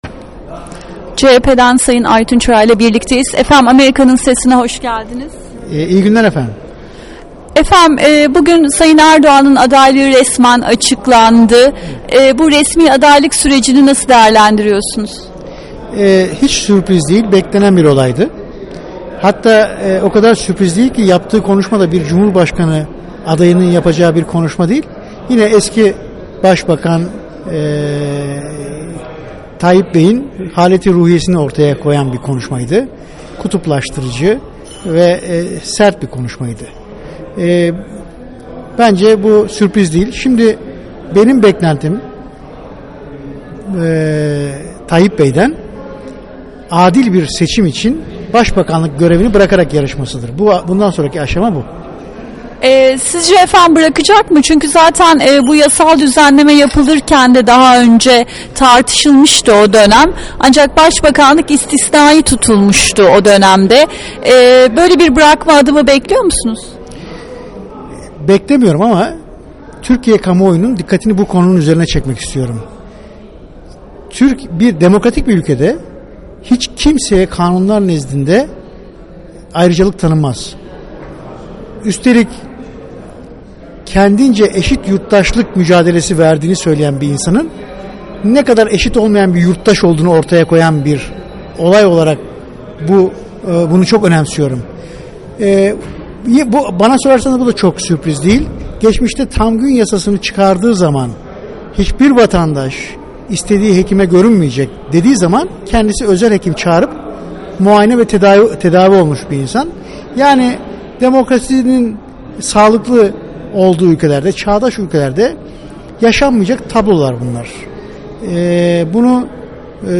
Aytun Çıray ile Söyleşi